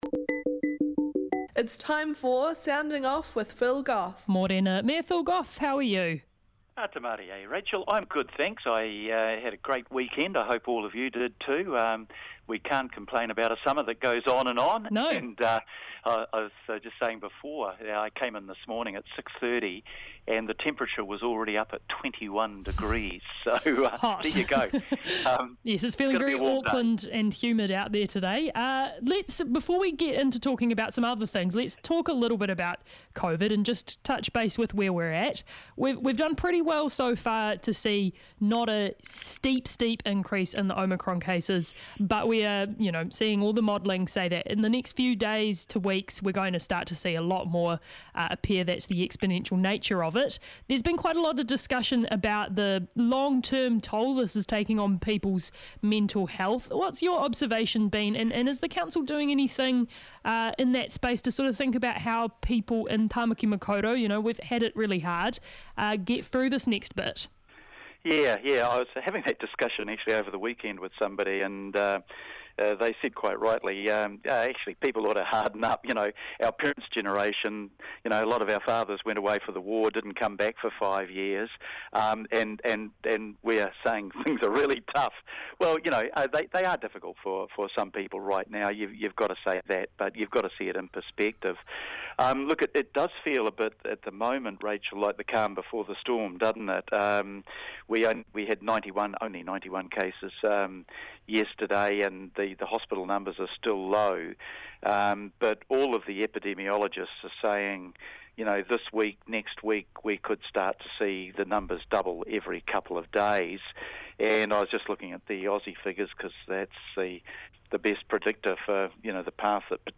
The Mayor chats about Omicron, the proposed new light rail route, and Auckland's Mayoral Elections.